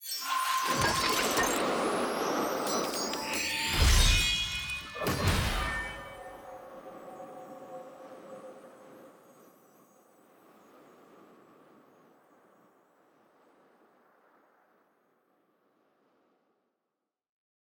sfx-clash-capsule-tier-3-ante-3.ogg